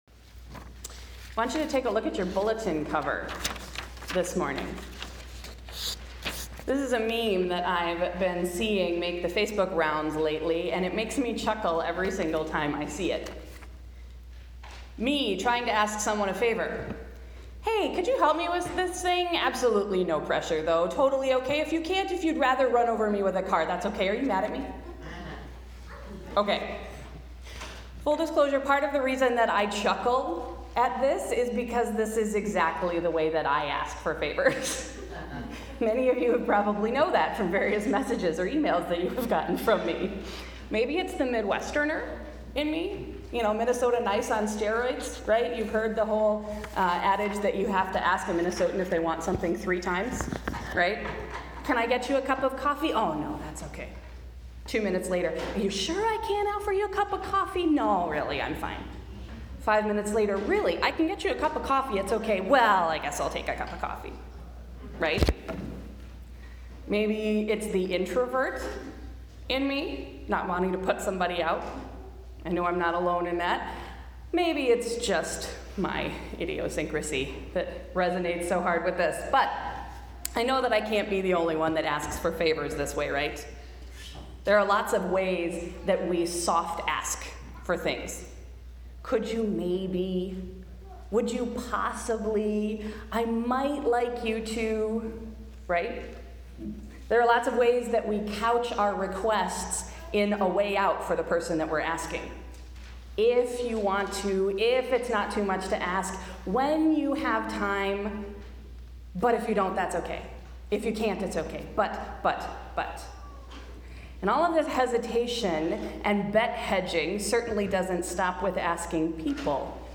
Sunday’s sermon: A Wildly Audacious Ask